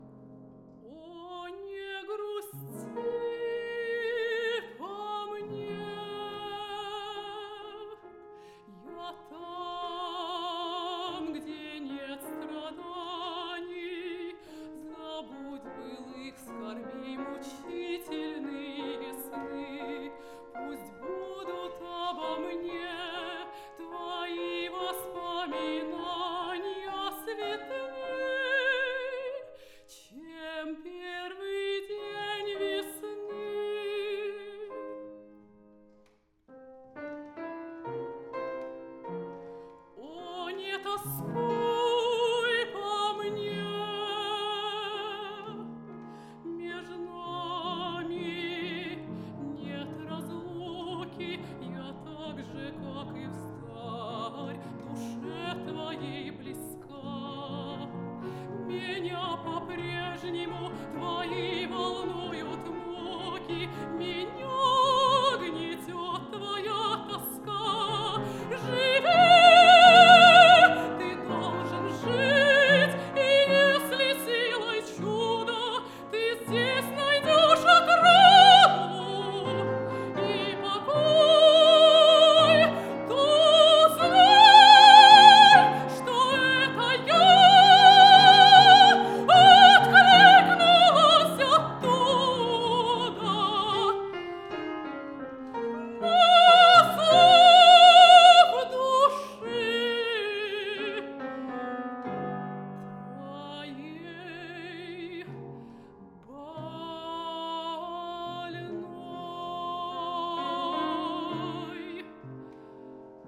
Я два раза только на этой неделе с ним сталкивался и вот, кстати, предлагаю кусочек свежатинки с концерта. Что не так здесь (кроме предательски-скрипучей сцены, на которую не будем обращать внимание)? Хочу мнение, максимально отдаленное от "дилетантизама"© 87 аи —> Саммит аудио (пару раз на форте отлично отработали аналоговые лимитеры, специально этот кусок выбрал) —> Саунддевайсес. Вложения u87_mezzo_sample.wav u87_mezzo_sample.wav 31,1 MB · Просмотры: 192